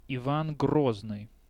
5. ^ Russian: Иван Грозный, romanized: Ivan Groznyy, IPA: [ɪˈvan ˈɡroznɨj]